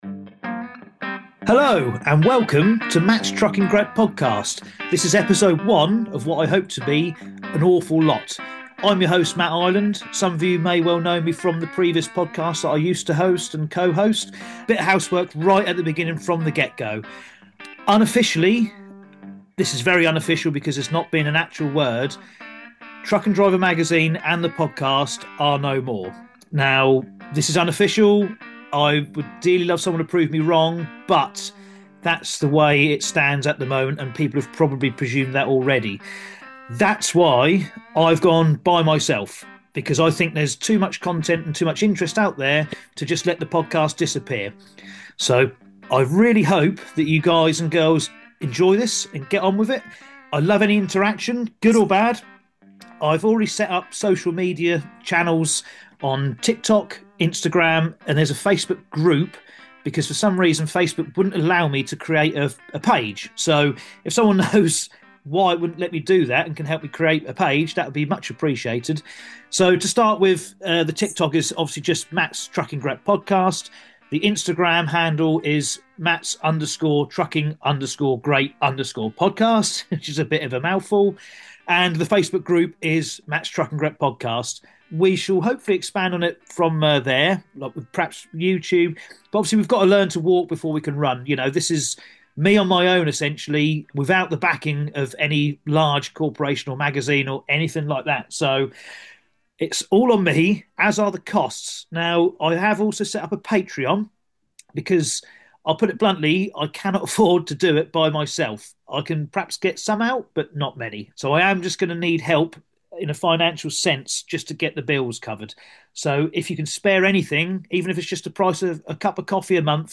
This is the podcast for truck drivers, hosted by and featuring interviews from people in the industry.